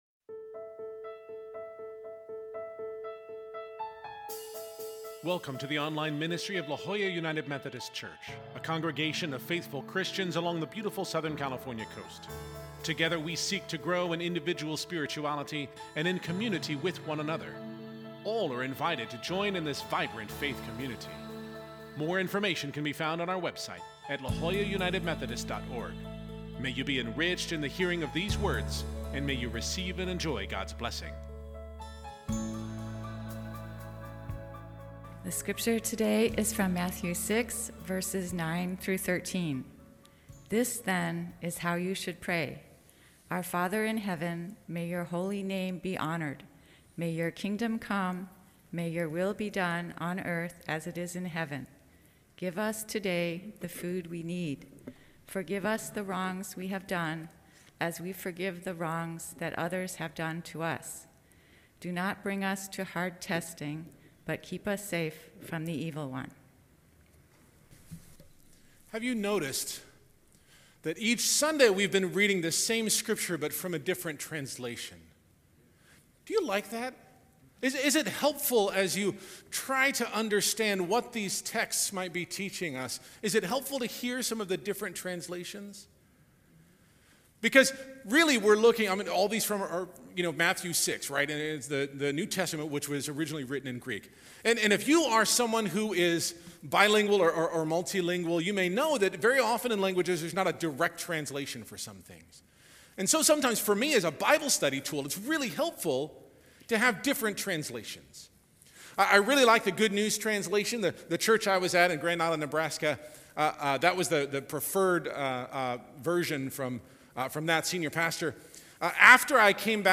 This week, we move to our penultimate service in our sermon series following The Lord’s Prayer, seeking God’s deliverance from all that tempts us. Just as we spoke last week about how we sometimes don’t realize that we are in need of forgiveness, I suggest it may be possible that we don’t realize when we are tempted.